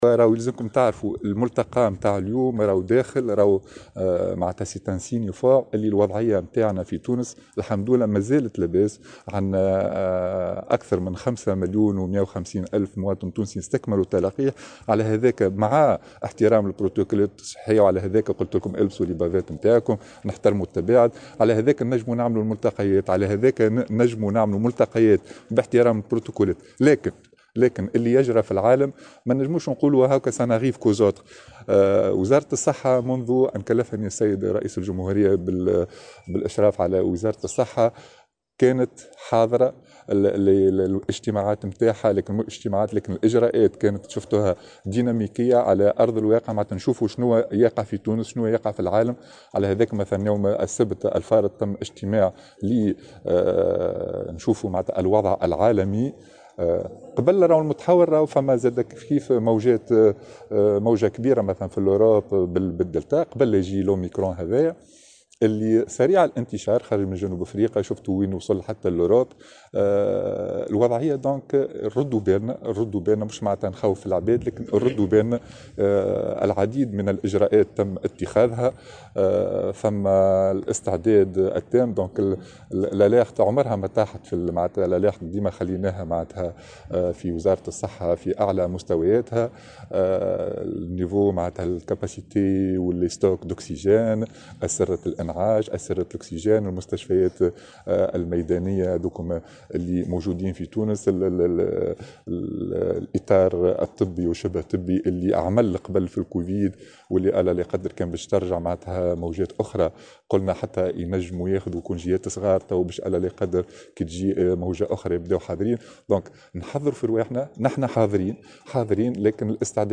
وأكد في تصريح إعلامي اليوم على هامش ندوة حول الشركة التونسية للصناعات الصيدلية، على ضرورة مواصلة التقيّد بالإجراءات الوقاية والبروتوكولات الصحية، مشيرا إلى أنه قد تم تشديد الإجراءات بالنسبة للوافدين على تونس بمختلف المعابر البرية والجوية والبحرية.